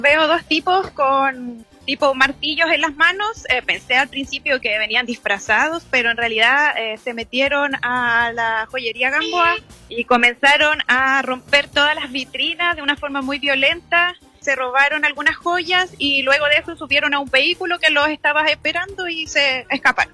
Una testigo relató que los delincuentes actuaban con mucha violencia, destruyendo todo a su paso, para finalmente huir del lugar en un vehículo.